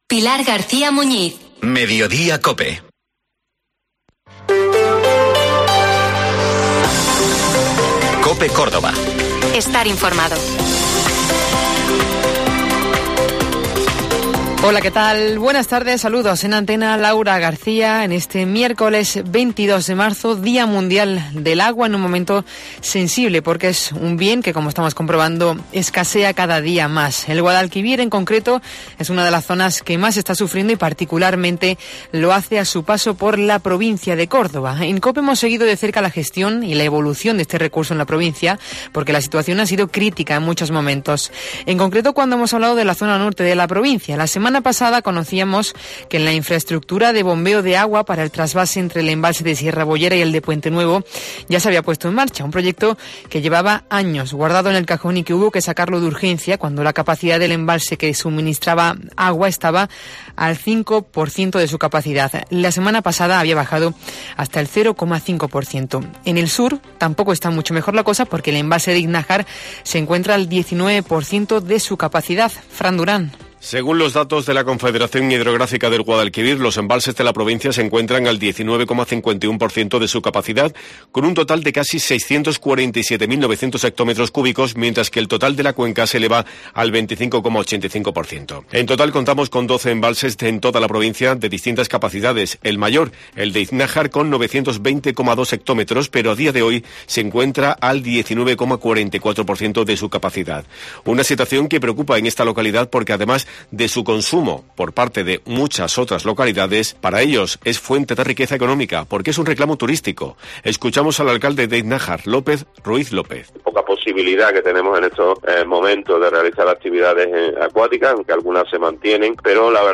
Informativo Mediodía COPE Córdoba